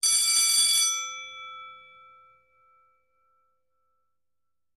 Bells; 8 School Bell.